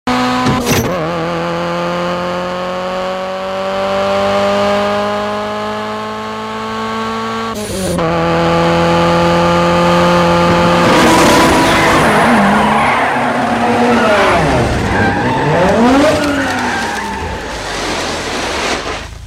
Brian’s Eclipse GSX – The OG Fast & Furious Sound 💚🚀